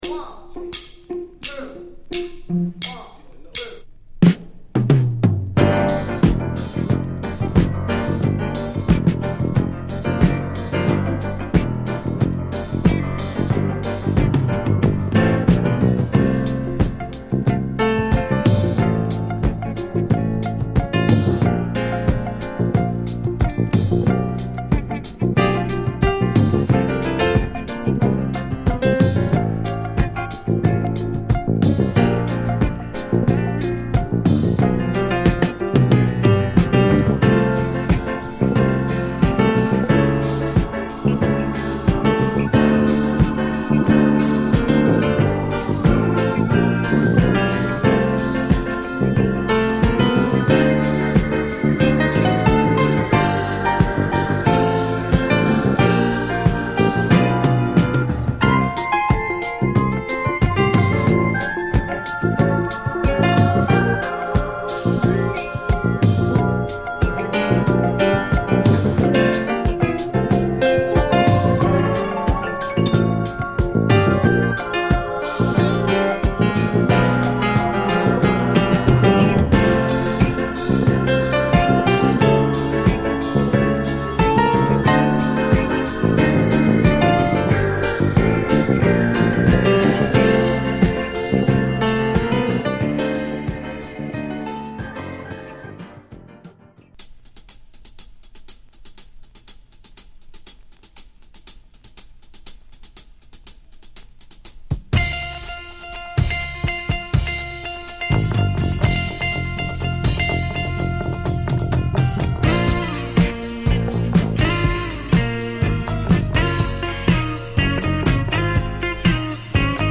括りはフュージョンなのですが、コレがファットでグルーヴィー！